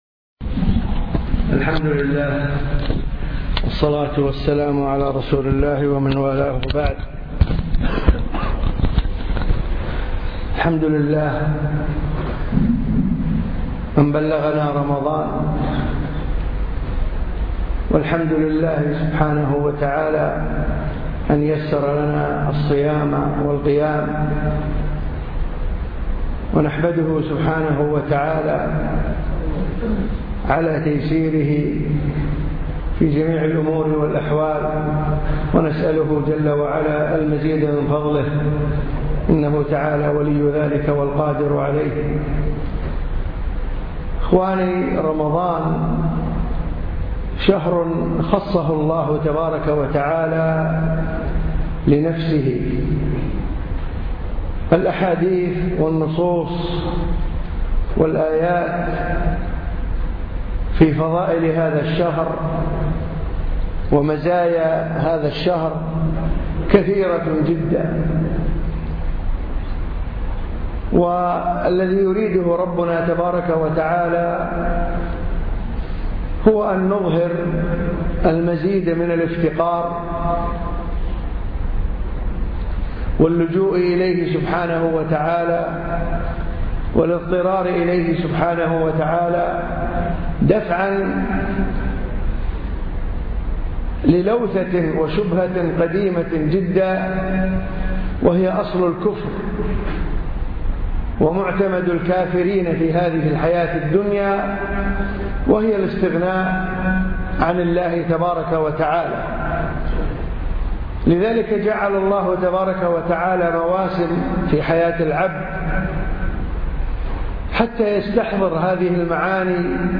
مجلس رمضاني